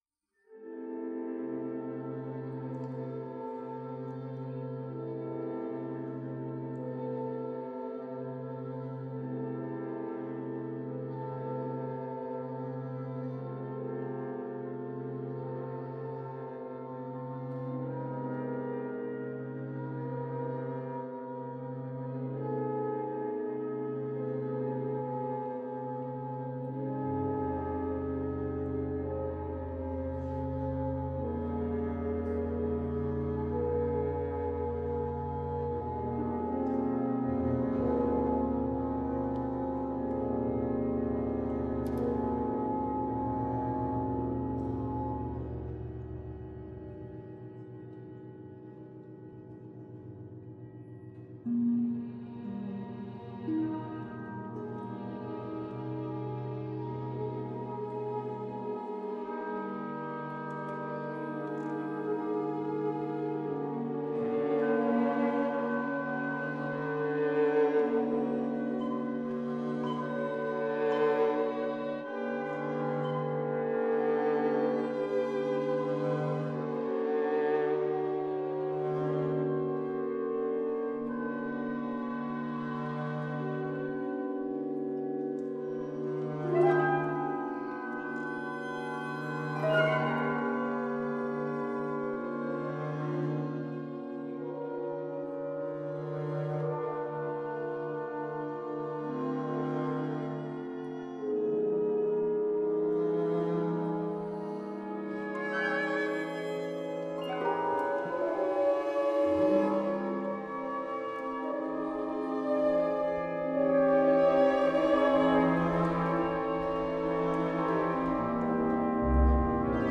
It is the third of the five pieces for orchestra, Op. 16, composed in 1909.
In these pieces, the expressionist setting and the atonal language that supports it reach the highest levels.
Farben begins with a five-note chord (C, G#, B, E, a) long, sustained passage that alternates between two instrumental groups: two flutes, clarinet, bassoon, viola, and an English horn, trumpet (muted), bassoon, horn (muted), and viola, with the double bass acting as a bridge.
It is a short piece: only 44 bars (just over 3'20") which, inserted in the midst of the expressionist fury of Op. 16, sound like a meditative interlude.